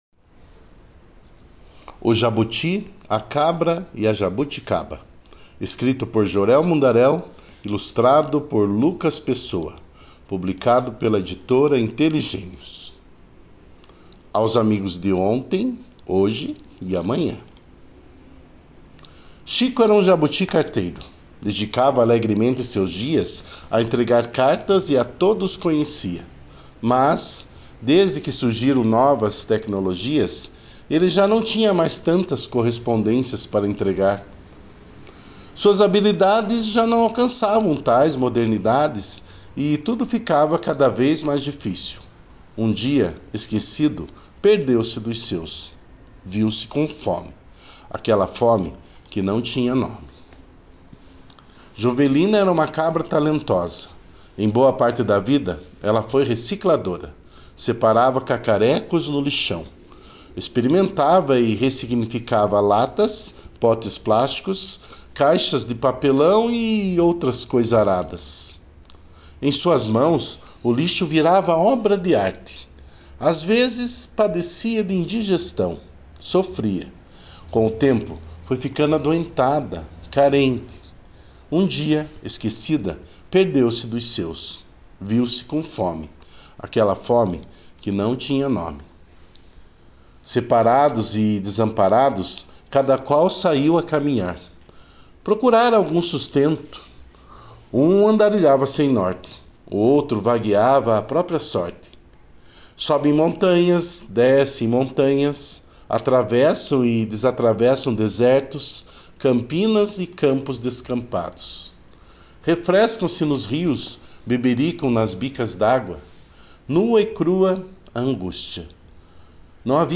Leitura Guiada
O-JABUTI-Audiobook.mp3